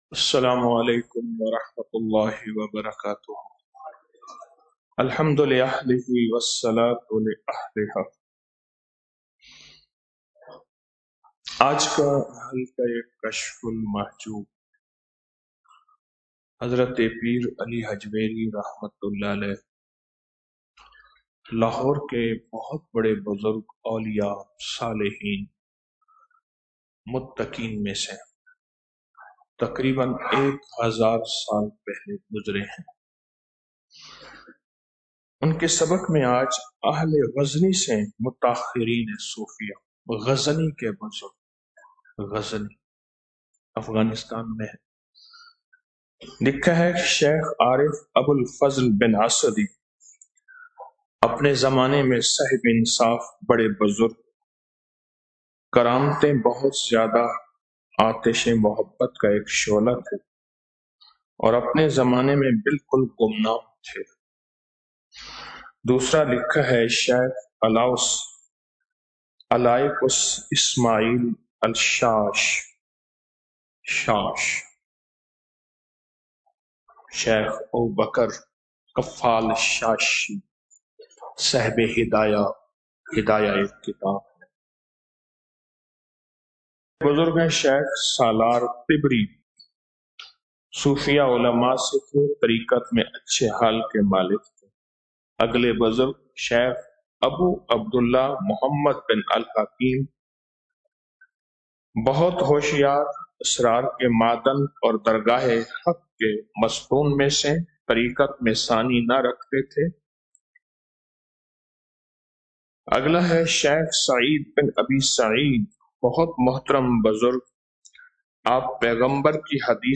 20 رمضان المبارک بعد نماز فجر- 21 مارچ 2025 ء - آڈیو درس 20-Ramzan-2025-Fajar-VOL-40.mp3 اس درس کو ڈاؤنلوڈ کرنے کے لۓ یھاں کلک کریں صدقہ جاریہ کی نیت سے شیئر کریں چند مزید درس سورج گرہن !